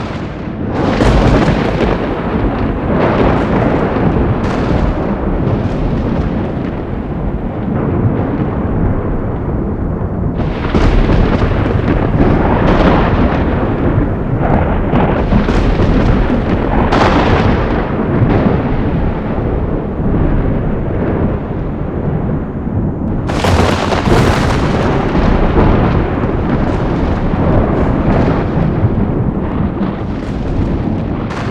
Thunder strikes 30 second- Loop.wav